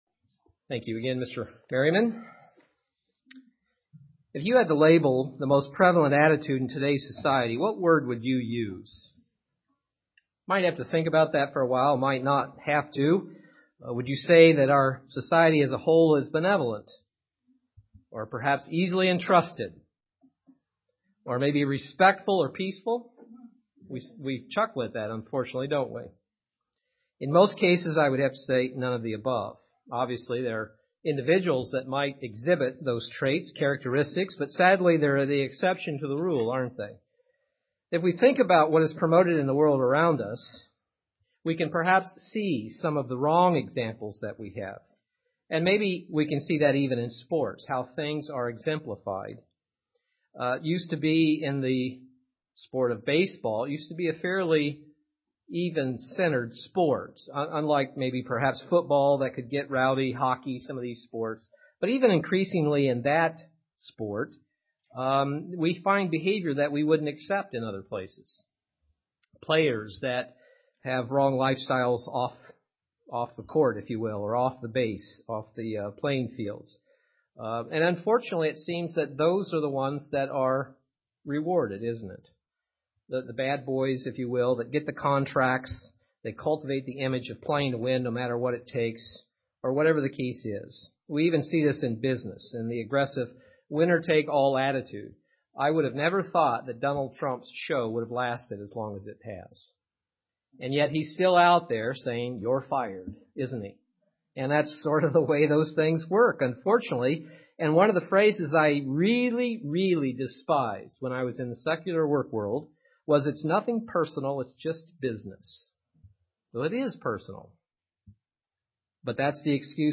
Pride is arrogant, snooty etc. UCG Sermon Studying the bible?